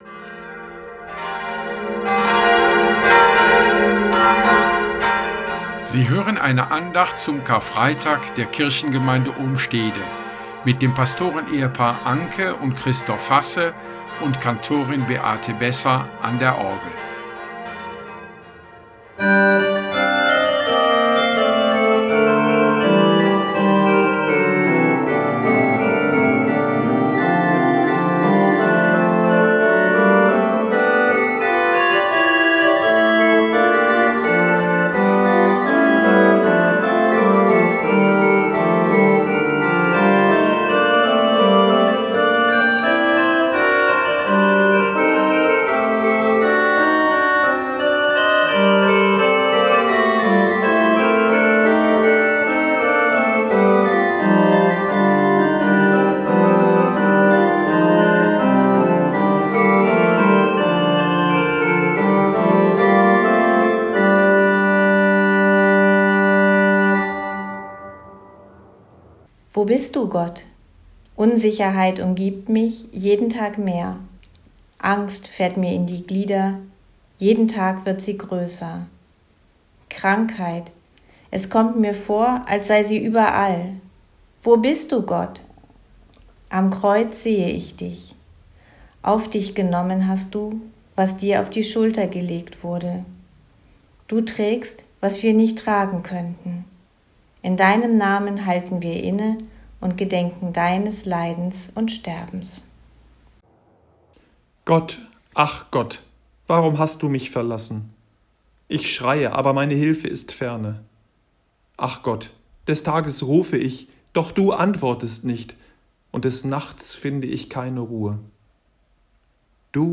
Diese Andacht wurde am Karfreitag, 10.4.2020 um 10:30 Uhrim Hörfunk von Oldenburg Eins gesendet.
Andacht zum Karfreitag